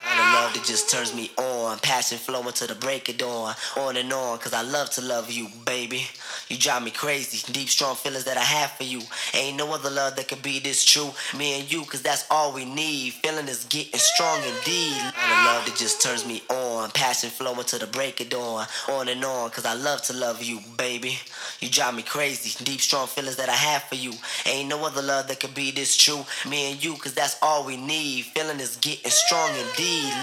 refrain_reverb.wav